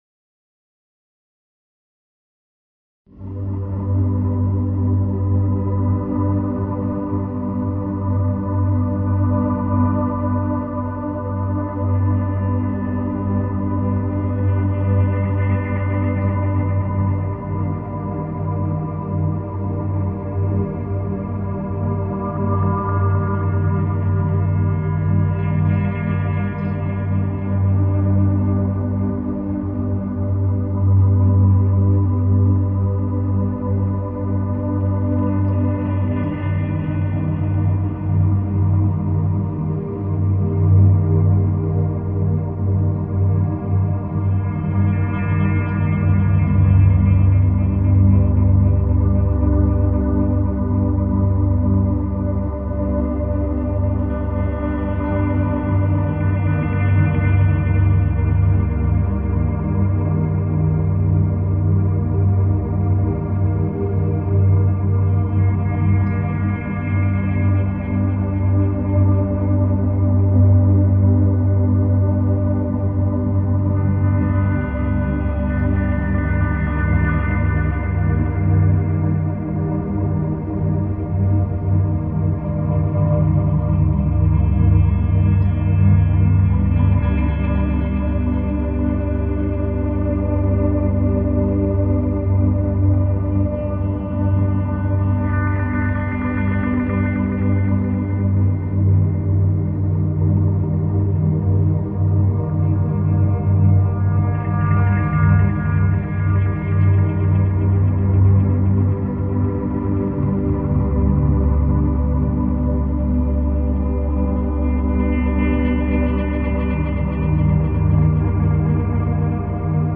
File under: Ambient / Dark Ambient